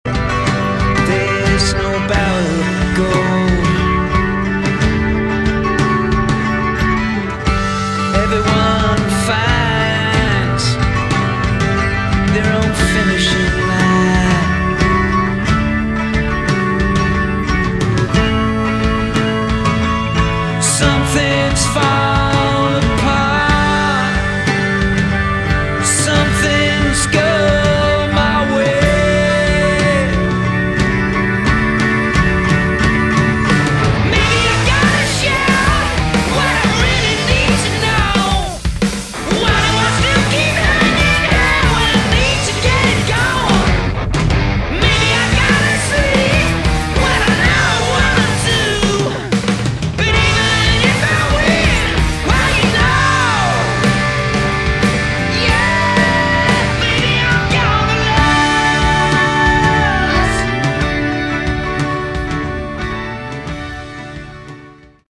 Category: Sleazy Hard Rock
vocals
electric & acoustic guitars, Mellotron
bass
drums (studio), strings